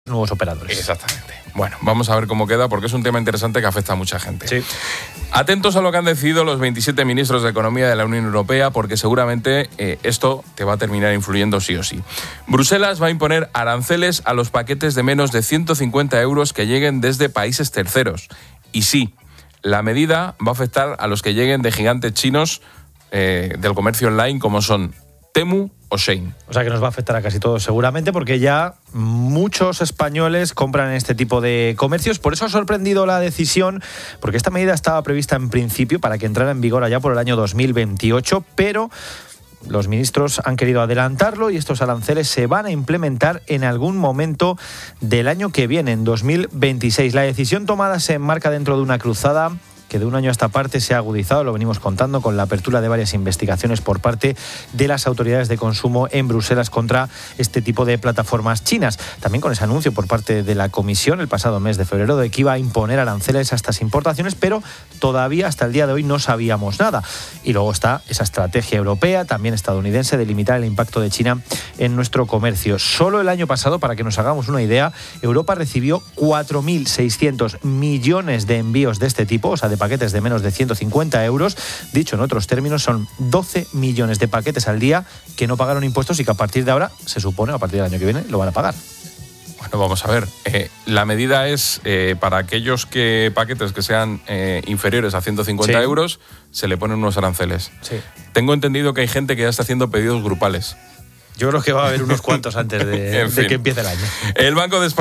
Periodista económico